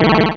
Cri de Barpau dans Pokémon Rubis et Saphir.